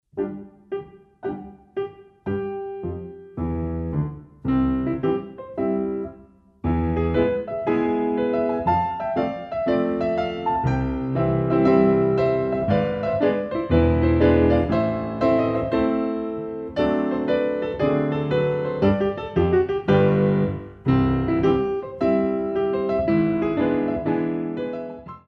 Young dancers Ballet Class
The CD is beautifully recorded on a Steinway piano.
Battement Jetés